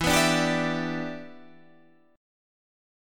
Em7 Chord